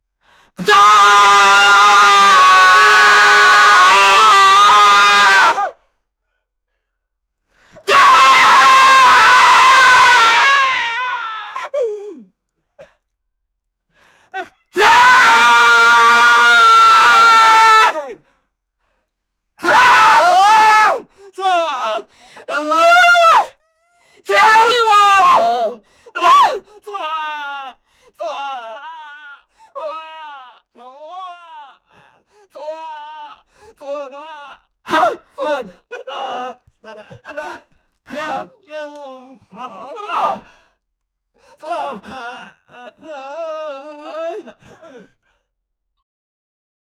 man-screaming-at-listener-wfnzofjn.wav